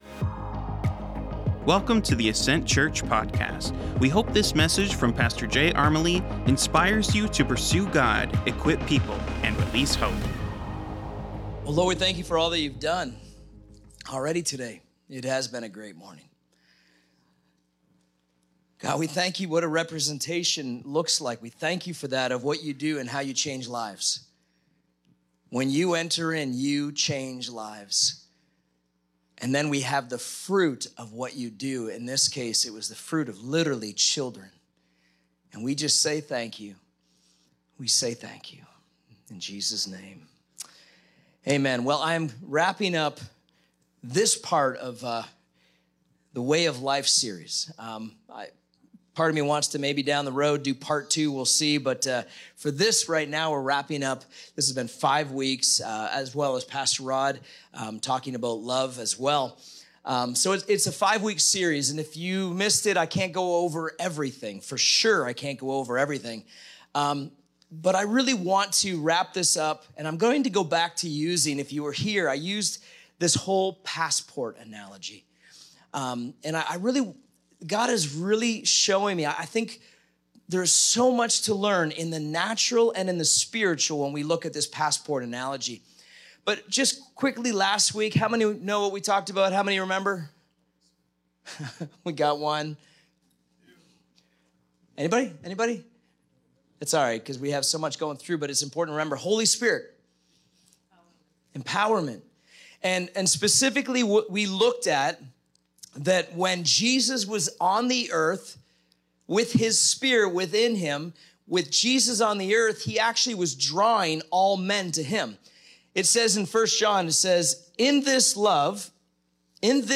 Current Weekly Sermon